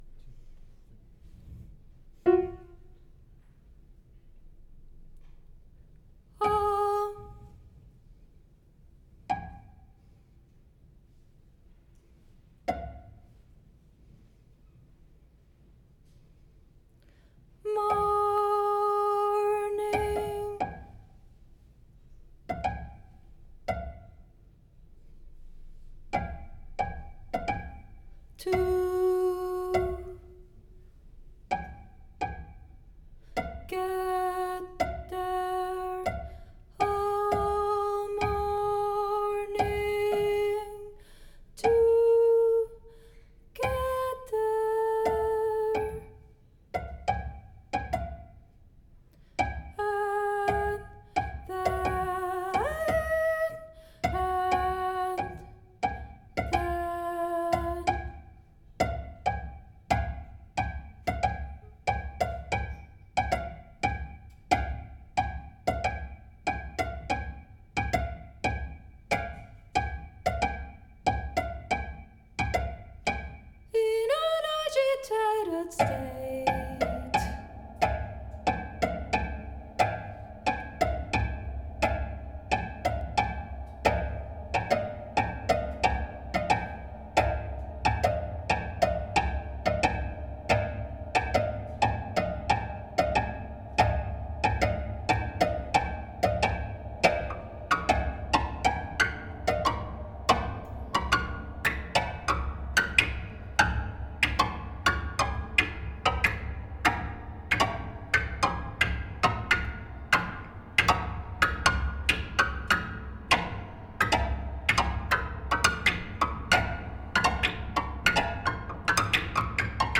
World premiere of compositions